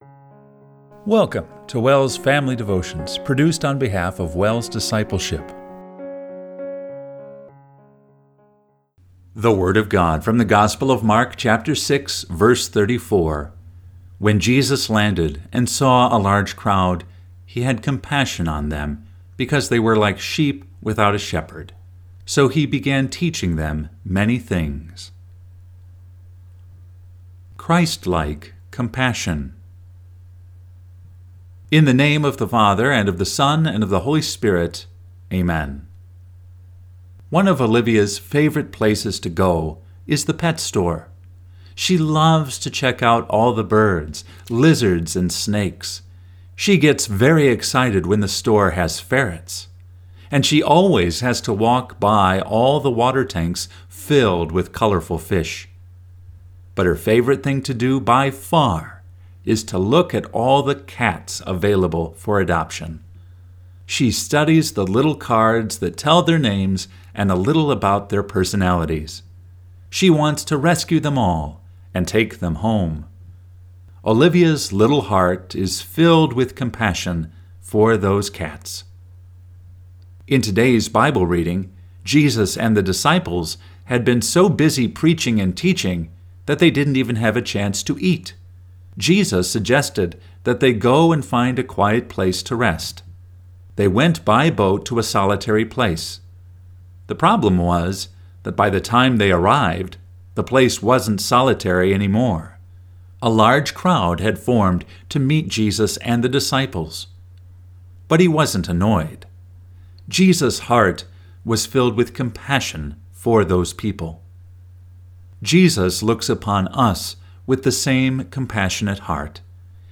Family Devotion – July 26, 2024